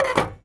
R - Foley 63.wav